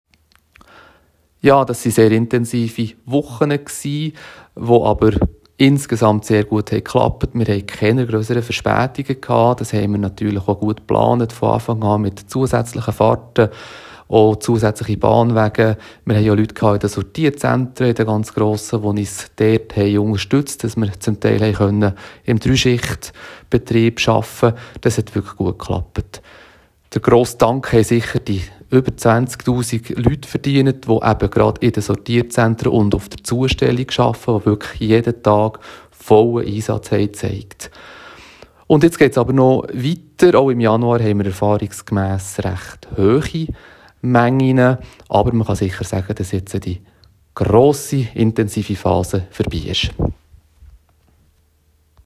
O-Ton für Radiostationen